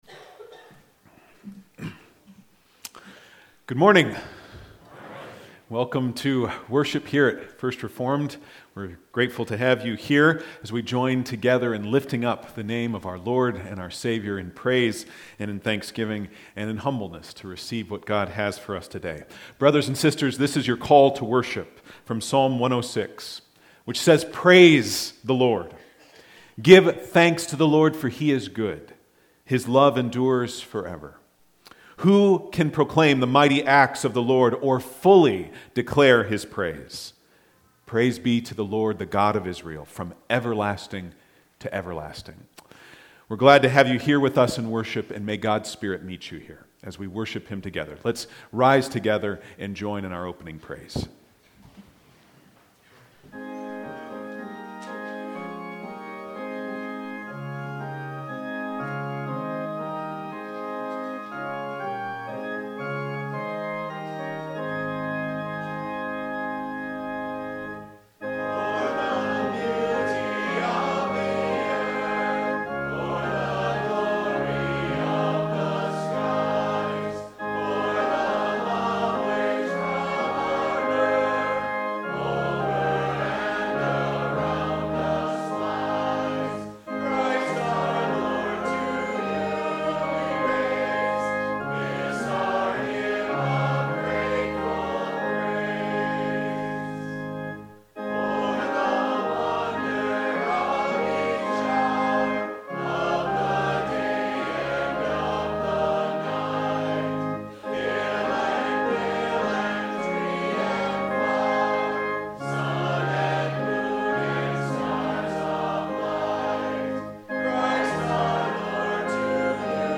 Sermons | Randolph First Reformed Church